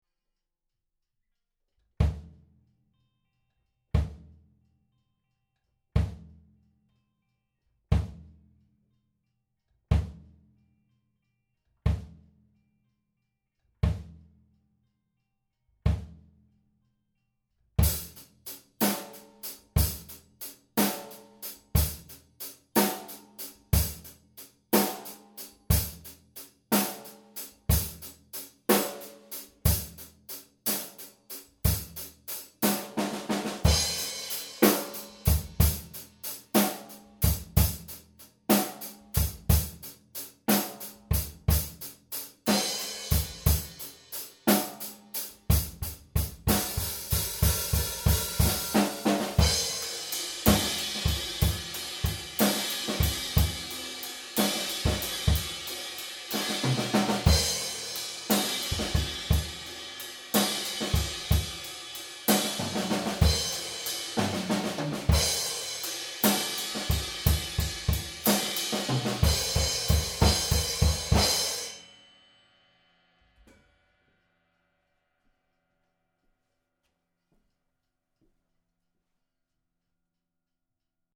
Daher habe ich mal versucht ein wenig was einzutrommeln und das ganze dann, mit meinen Mikros in der Mid/Side Variante aufzunehmen.
Die verwendeten Mikros sind MXL R144 Bändchen Mikrofon, welches als Seiten Mikrofon genutzt wurde.
Das Mitten-Mikro ist das Rode NT-1000. Die Spuren sind unbearbeitet, abgesehen vom Panning und der Phaseninvestierung.
Das verwendete Kit ist übrigens ein Yamaha Stage Custom, falls das für irgendwen von Relevanz sein sollte Anhänge Drum Test Mid:Side .mp3 1,6 MB · Aufrufe: 215